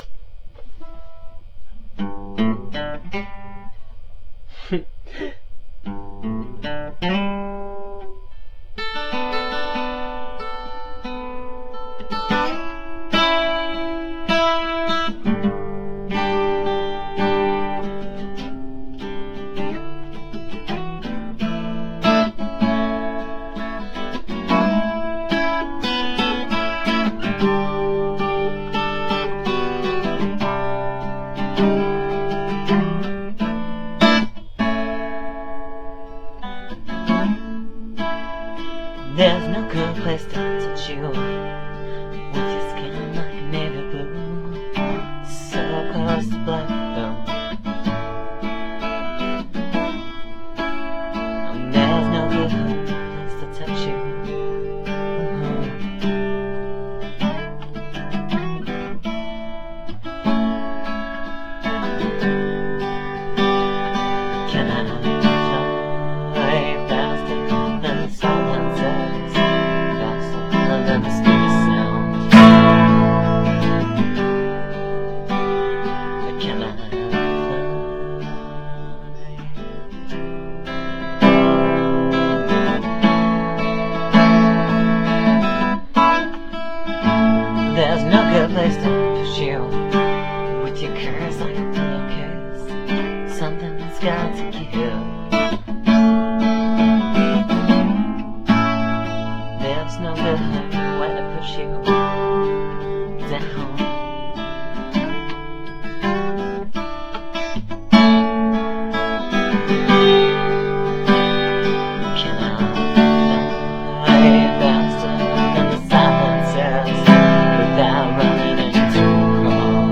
acoustic guitar and voice